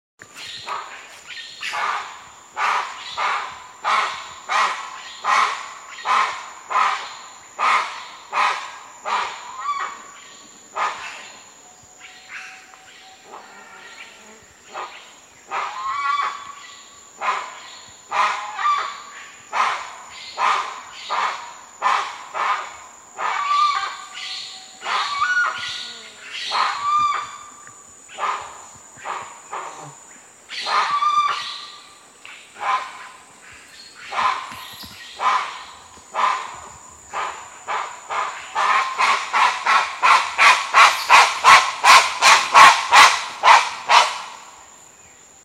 Canto do jacumirimé
O jacumirimé dá uma exibição de zumbido de asas, geralmente antes do amanhecer, e geralmente o segue com um latido “whaf, whaf, whaf”. Também dá os latidos ao entardecer.
Canto-do-jacumirime.mp3